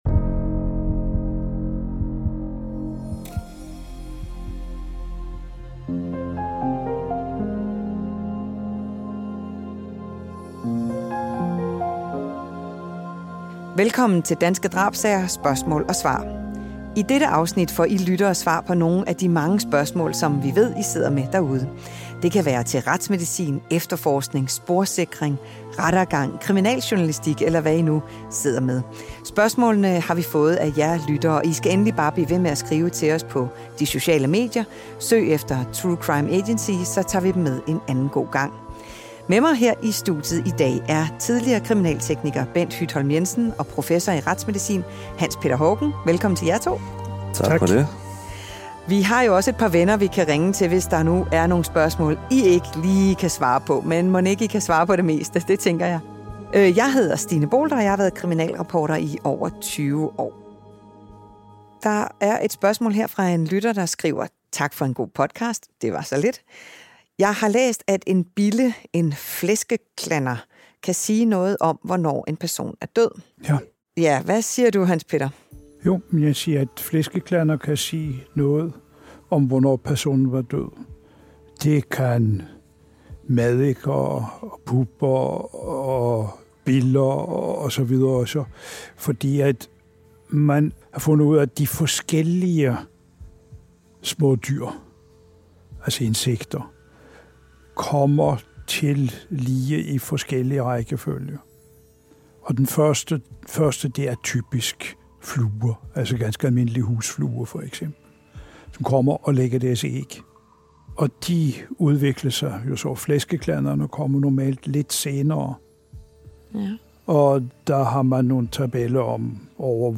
I dette særafsnit svarer vores fageksperter på spørgsmål fra jer lytter som: Hvordan kan biller og larver sige noget om et dødstidspunkt?